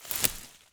harvest_6.wav